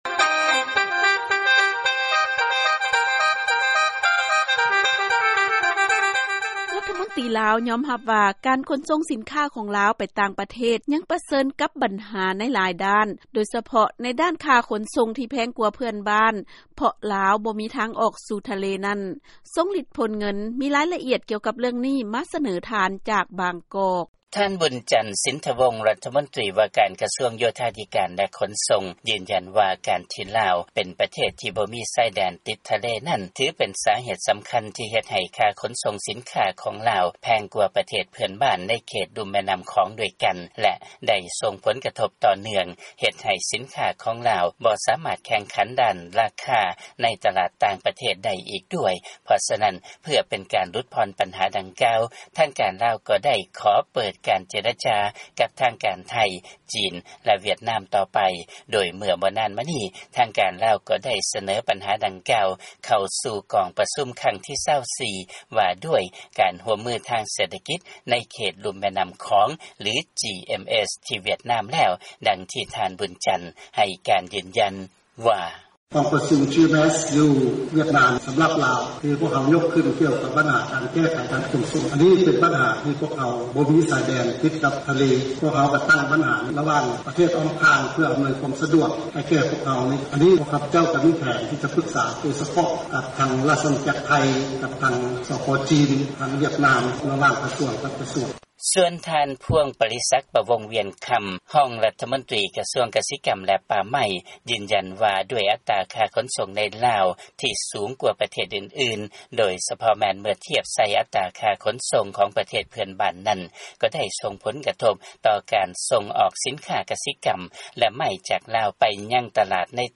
ມີລາຍງານມາສະເໜີທ່ານຈາກບາງກອກ.